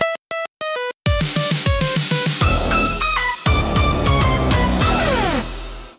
cool